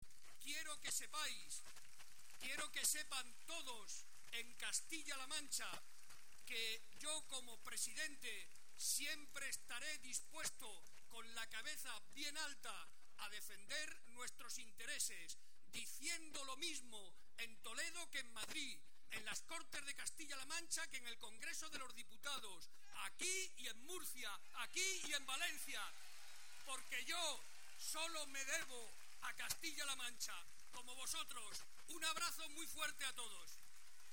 Barreda, que hoy participó en la concentración que se celebró en Talavera de la Reina (Toledo) en defensa del río Tajo, intervino ante las más de 15.000 personas que asistieron para recordar que siempre estará dispuesto, con la cabeza bien alta, “a defender nuestros intereses diciendo lo mismo en Toledo que en Madrid, en las Cortes regionales que en el Congreso de los Diputados, aquí y en Murcia, aquí y en Valencia”.